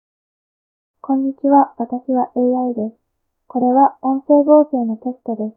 筆者も彼女もとても似ている音声となった。
womanAI.wav